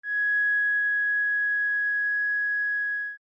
Ab6.mp3